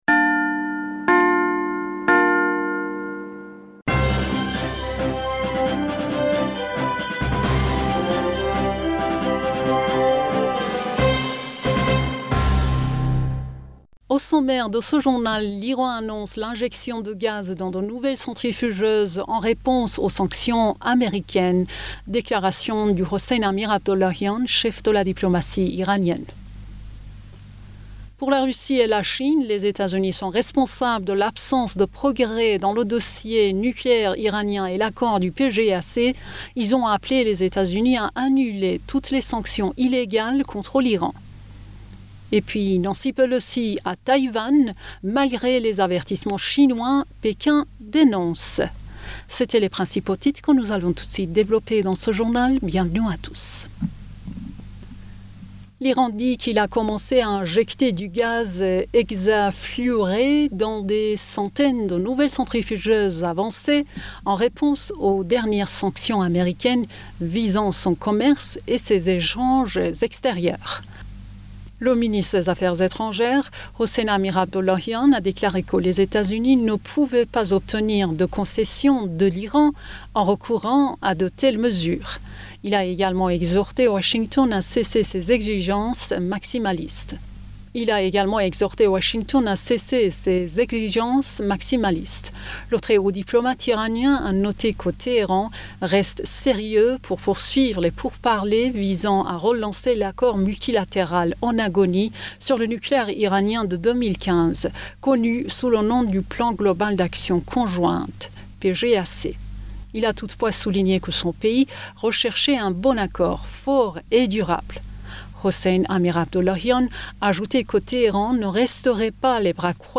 Bulletin d'information Du 03 Aoùt